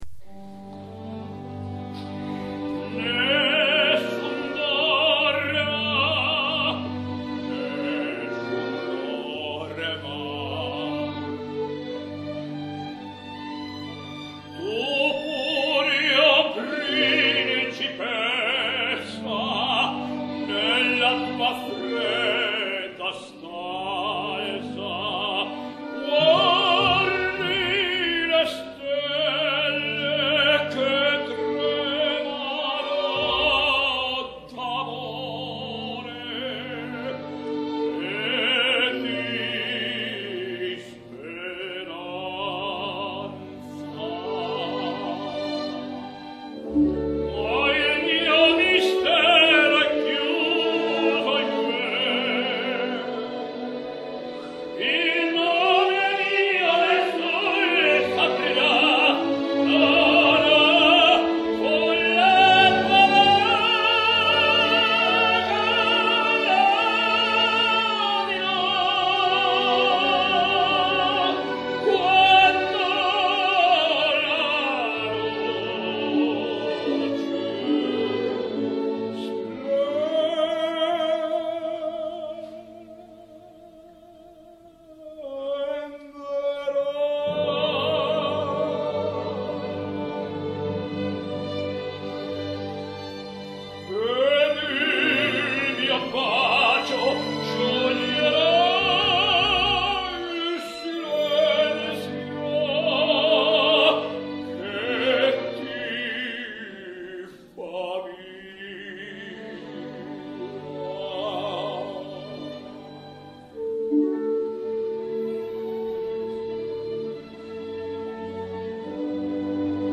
Tenors singing Nessun dorma